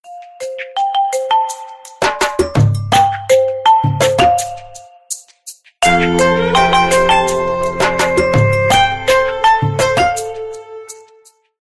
Phone Ringtones